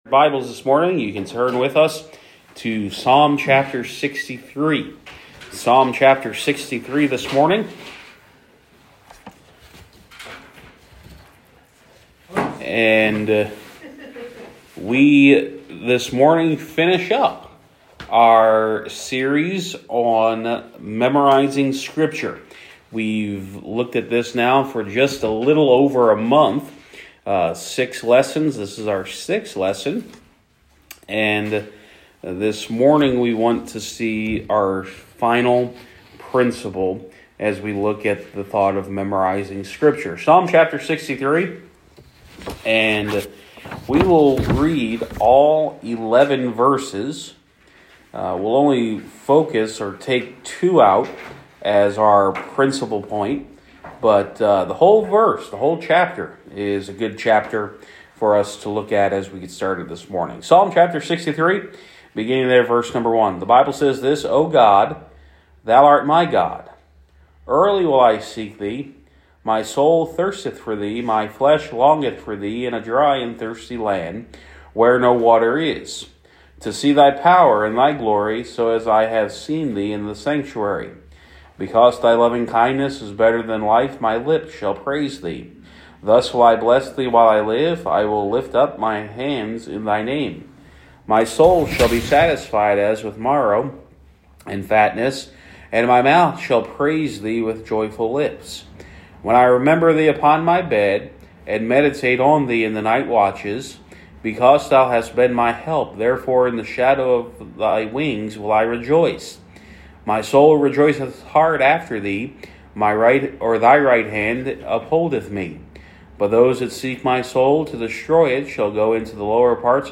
Sermons | First Baptist Church of Sayre, PA